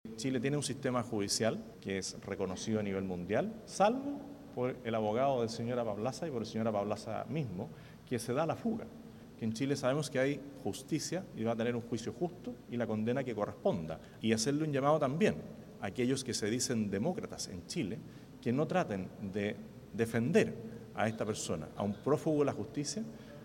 En medio de este contexto, el Presidente Kast dijo que habló con Milei de la situación; le agradeció por la celeridad que han dado al caso y reiteró que está seguro de que tarde o temprano el exfrentista rendirá cuentas ante la justicia chilena.